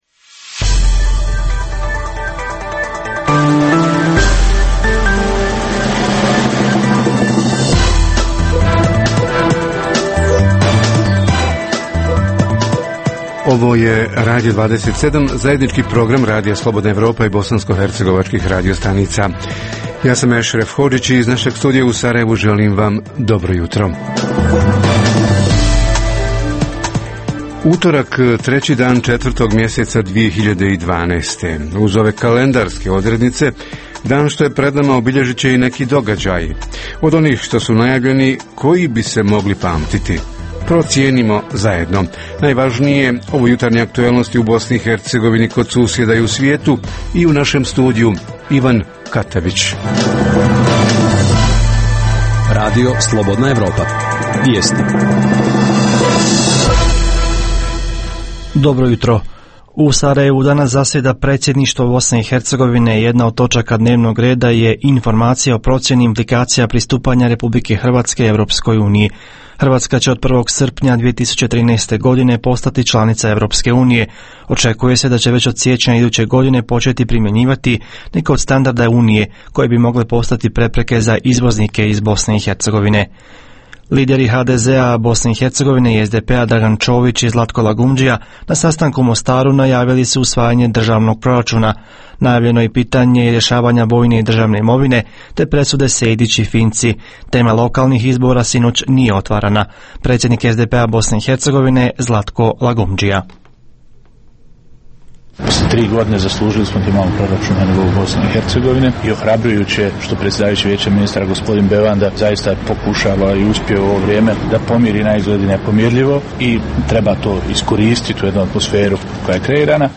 Predškolski odgoj i obrazovanje – zašto je tim vidom edukacije u BiH obuhvaćeno samo 10 % djece od 3 do 6 godina i ko i šta poduzima da taj obuhvat bude veći? Reporteri iz cijele BiH javljaju o najaktuelnijim događajima u njihovim sredinama.
Redovni sadržaji jutarnjeg programa za BiH su i vijesti i muzika.